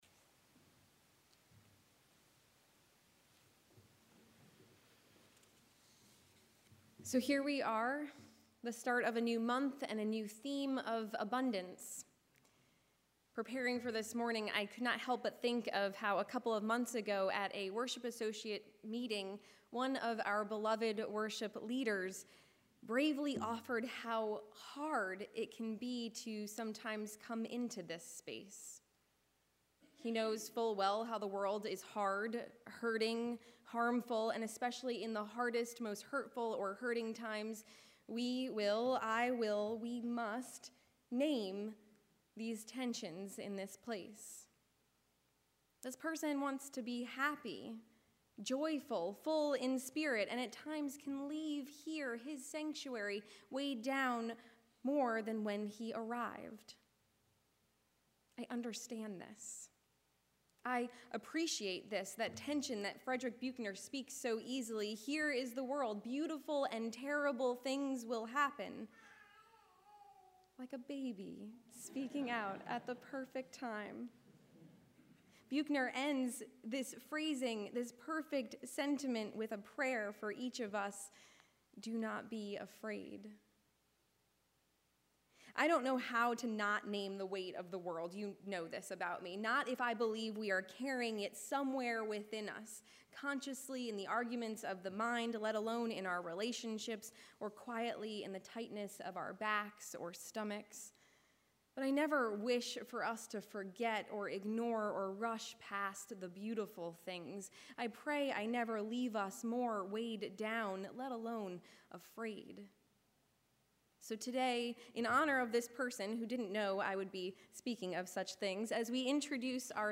This Sunday, let’s begin by only telling stories of Joy this week. The Crossing Chorale will be singing.